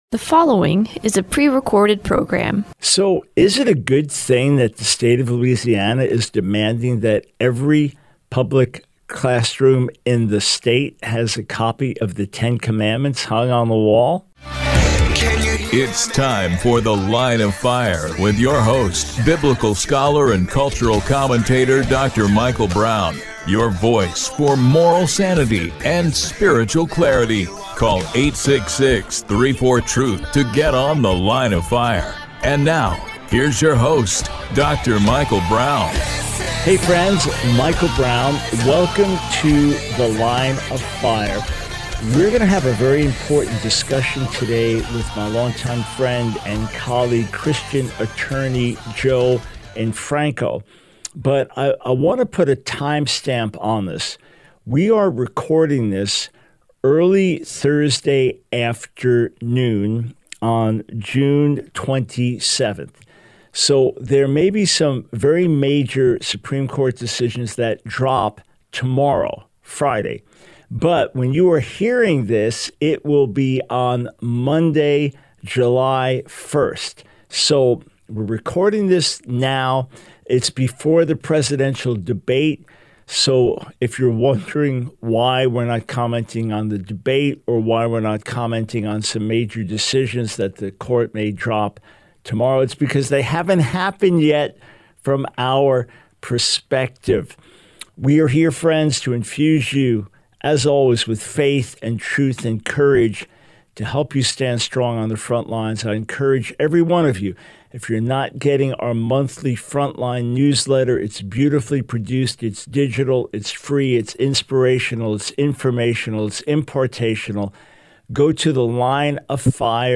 The Line of Fire Radio Broadcast for 07/01/24.